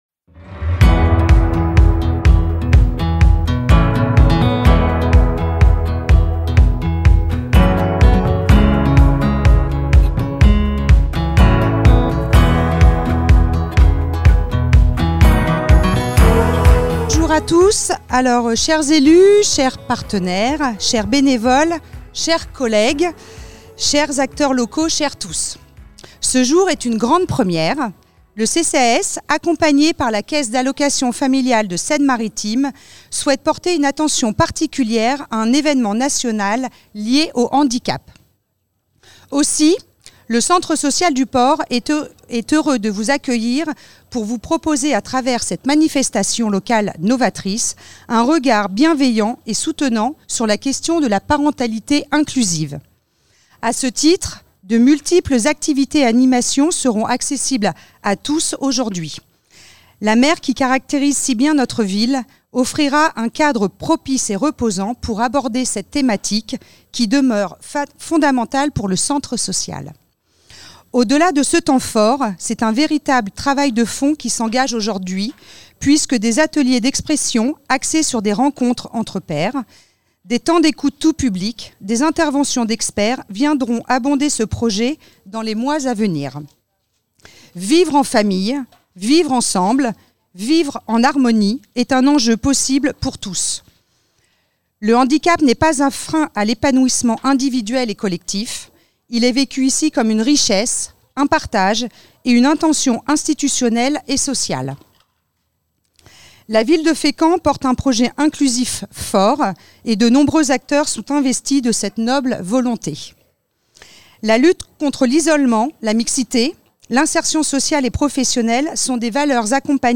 La nuit du Handicap 2025 - 02 - Discours des élus du 14.06.2025